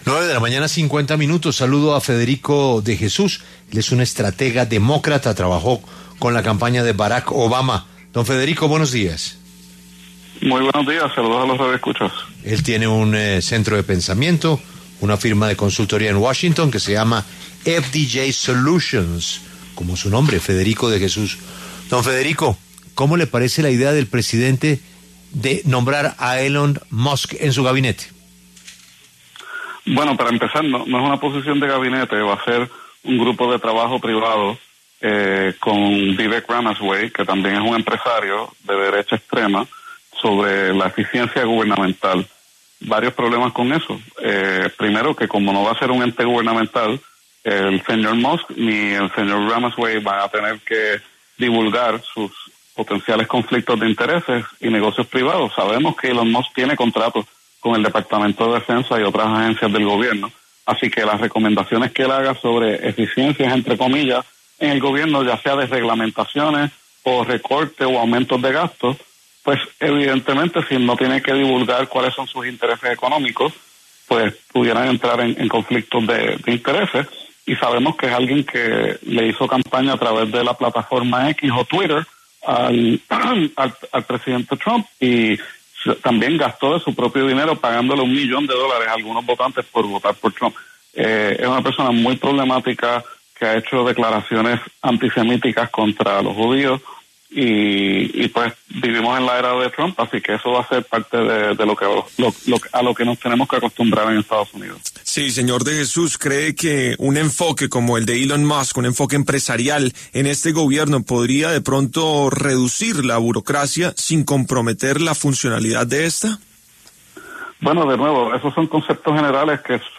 En La W hablaron dos analistas políticos, uno demócrata y otro republicano, quienes explicaron desde sus perspectivas cuál será el papel de Elon Musk en la administración Trump.